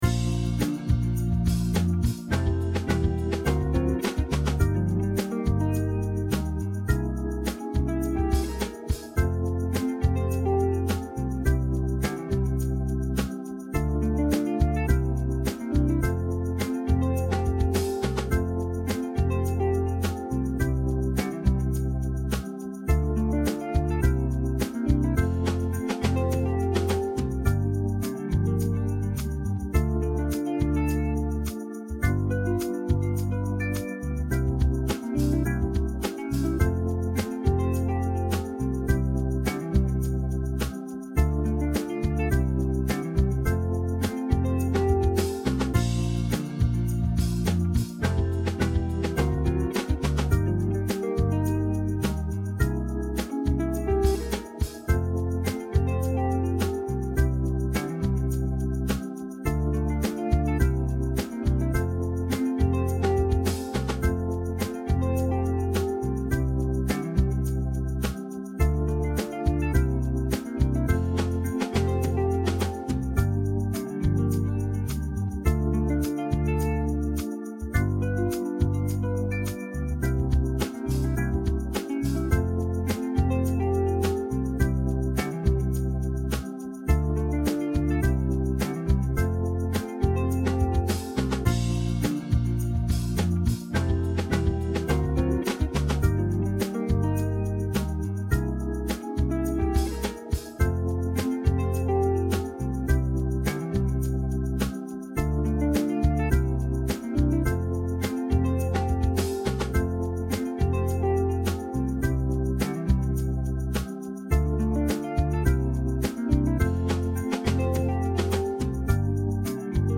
Au Clair de la lune - Spielsatz für UkulelenEnsemble
Halbplayback
Au-Clair-de-la-lune-Playback.mp3